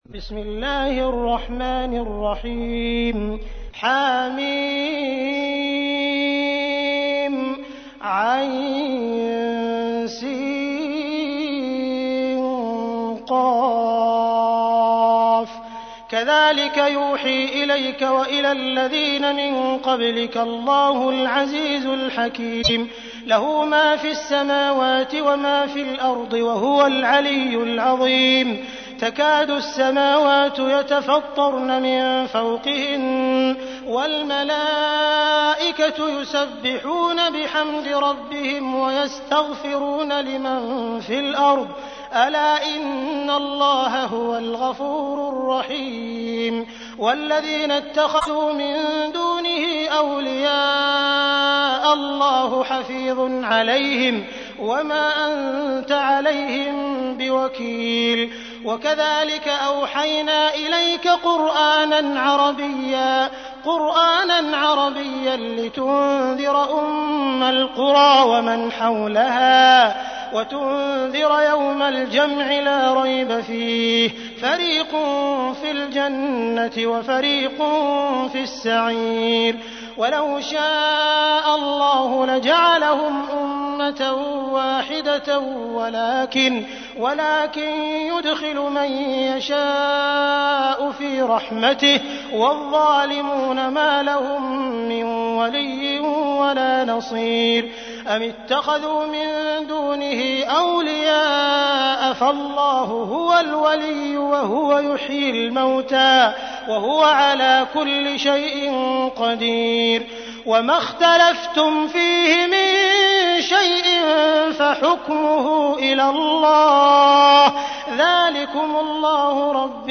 تحميل : 42. سورة الشورى / القارئ عبد الرحمن السديس / القرآن الكريم / موقع يا حسين